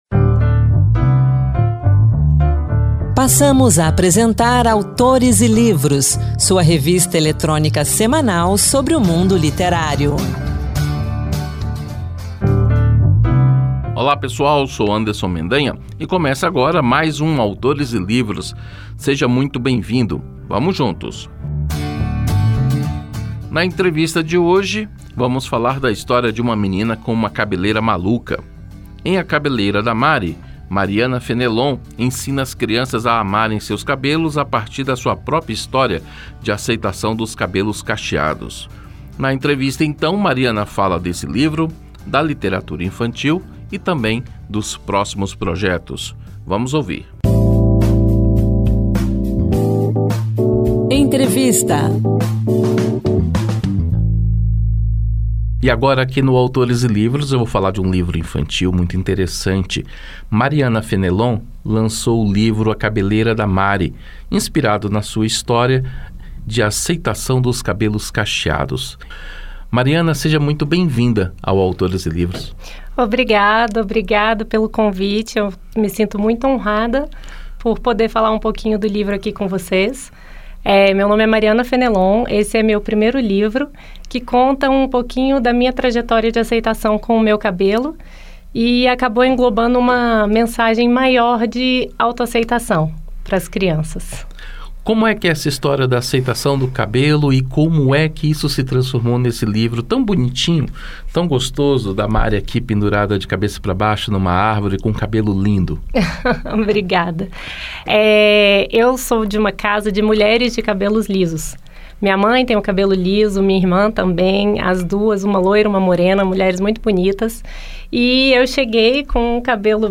O Autores e Livros desta semana traz uma entrevista